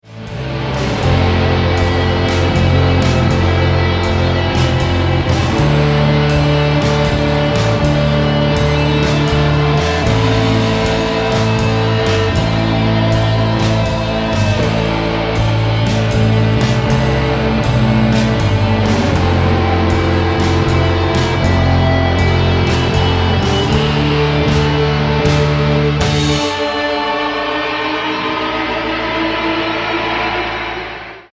drums, rhythm guitar, lead guitar